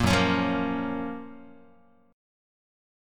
GbM7 chord